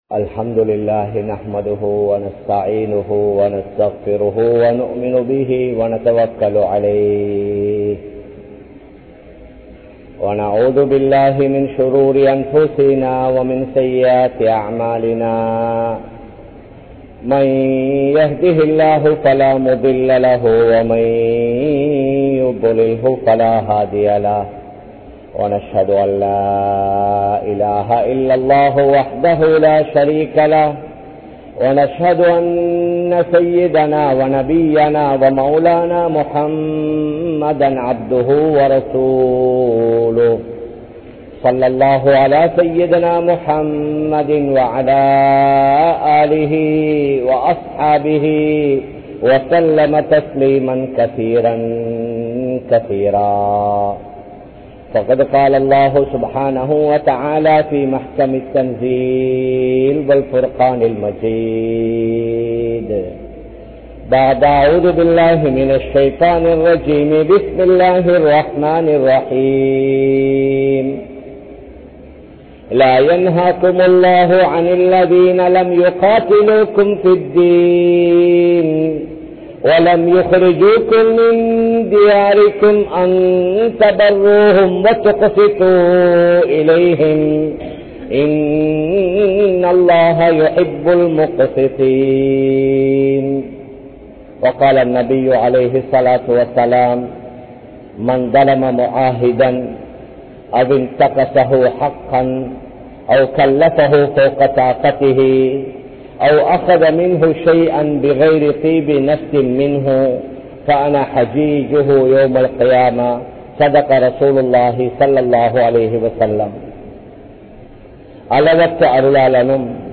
Ottrumaiyaaha Vaalvoam (ஒற்றுமையாக வாழ்வோம்) | Audio Bayans | All Ceylon Muslim Youth Community | Addalaichenai